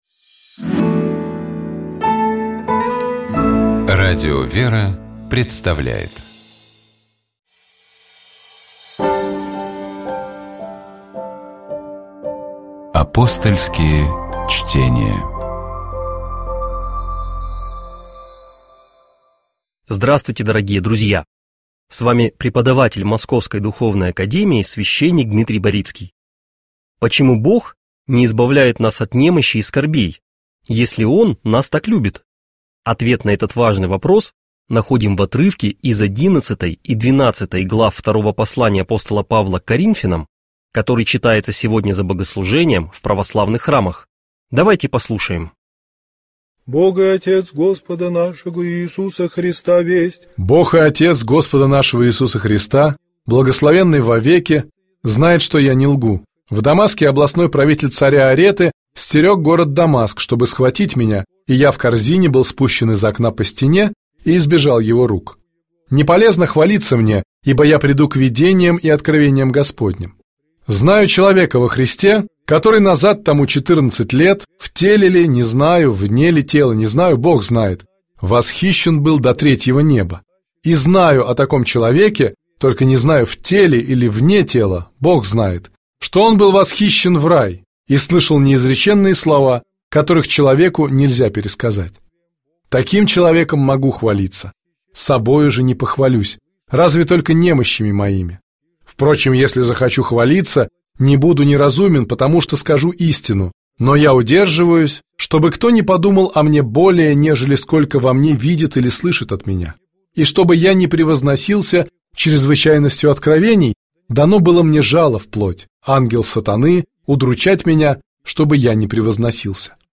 Апостольские чтения